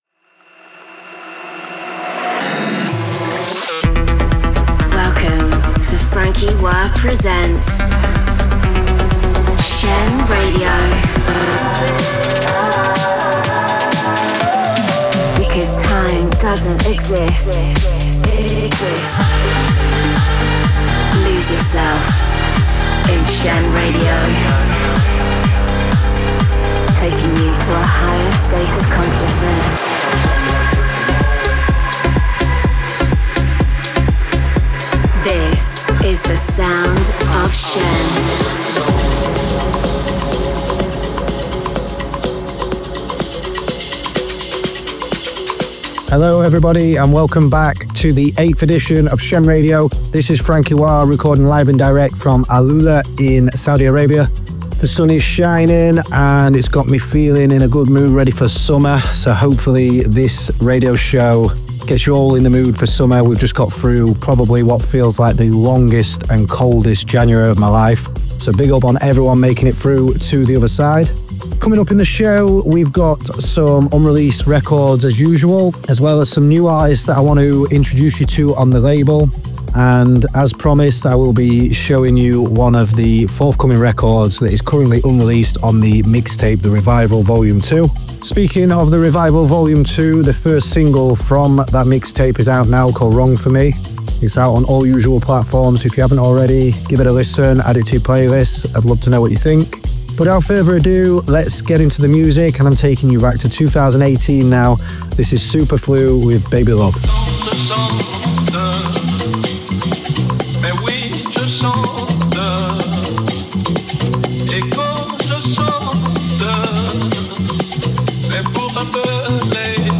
conceptual, cutting-edge, progressive sounds